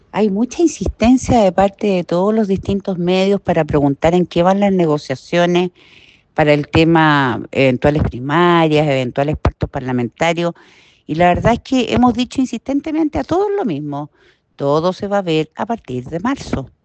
La diputada del Partido Nacional Libertario, Gloria Naveillan, aclaró que cualquier determinación de este tipo será anunciada a finales de marzo.